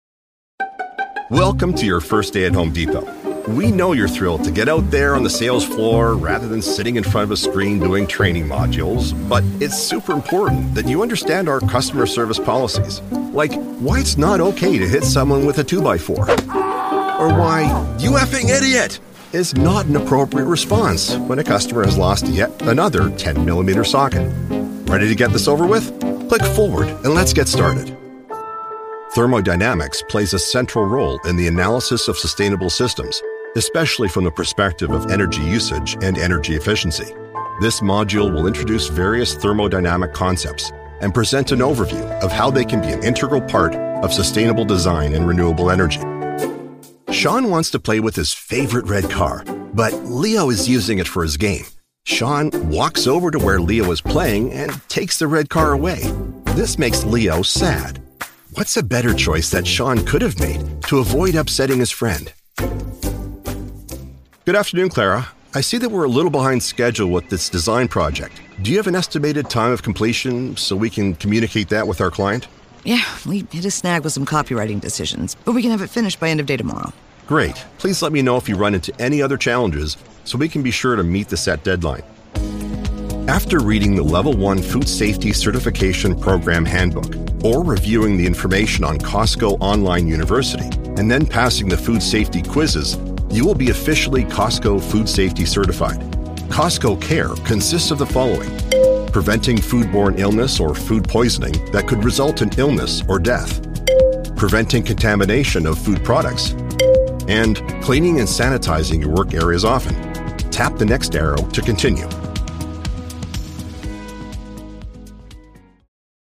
English (Canadian)
E-learning
CAD E100S large diaphragm condenser microphone, Steinberg UR22 interface, Mogami cables, custom-built recording studio, Mac Mini running Adobe Audition CC and full connectivity including SourceConnect Standard for directed sessions.
BaritoneBassDeepLow